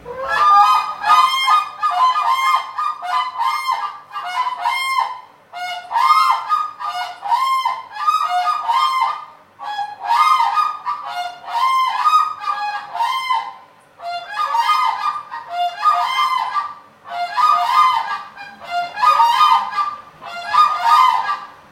Black-necked Crane
Unison Call | A duet performed by a pair, to strengthen their bond and protect their territory.
Black-necked-Crane-Unison.mp3